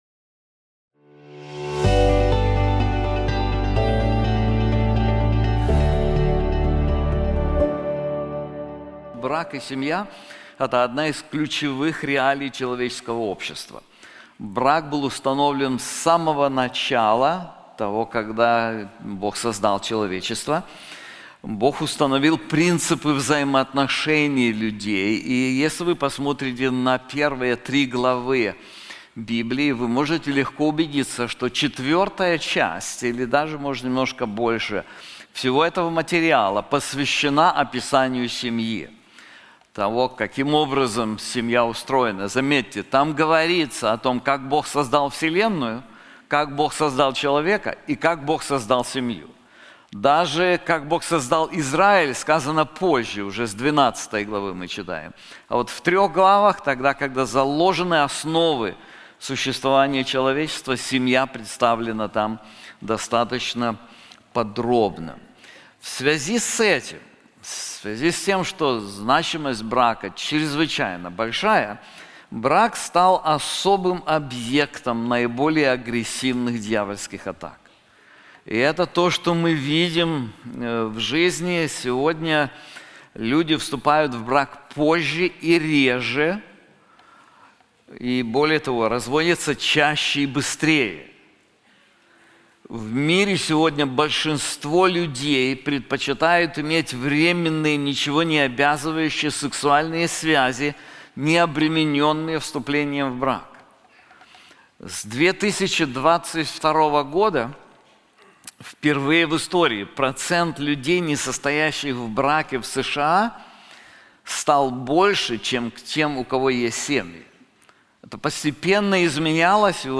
Как сохранить семью и сделать ее местом благословений? В этой проповеди, в свете Писания мы посмотрим на практические уроки многолетнего опыта в браке обычных христиан, делающие прочный и благословенный брак возможным и в наши дни.